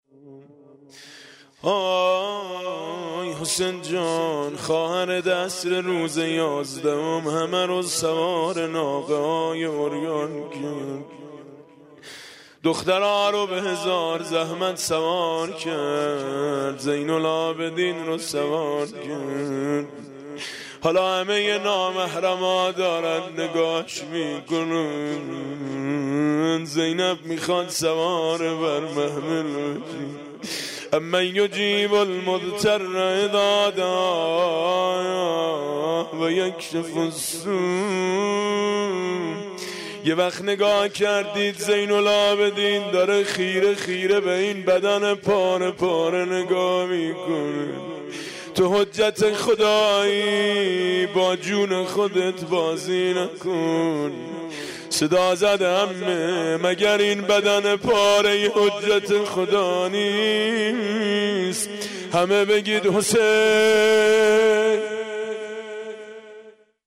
روضه خوانی میثم مطیعی در مقبره شهدای گمنام